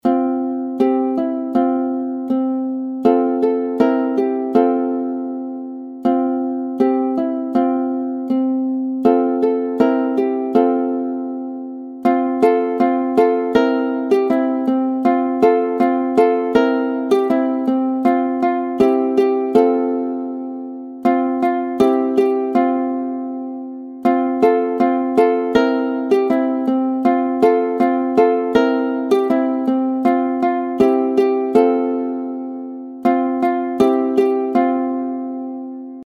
Tradycyjna polska kolęda
w tonacji F-dur opracowana w stylu fingerstyle na ukulele.
Instrument Ukulele
Gatunek Kolęda